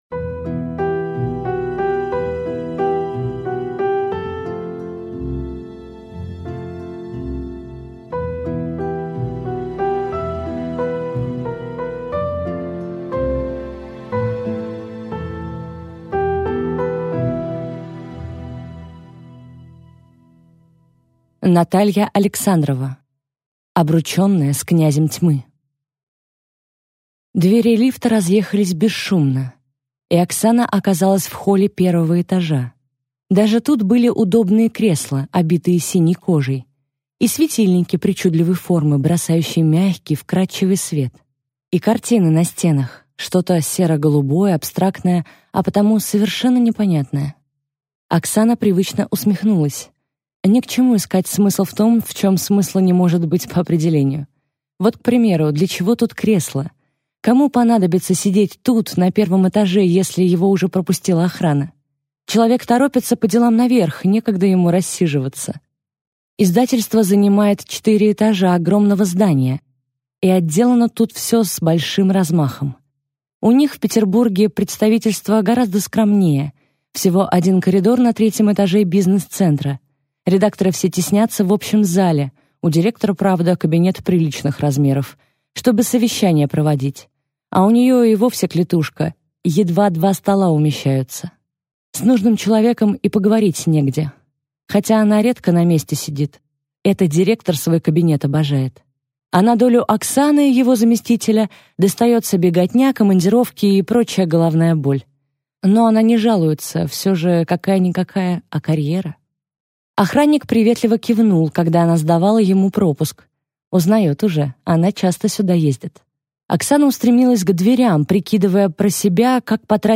Аудиокнига Обрученная с Князем тьмы | Библиотека аудиокниг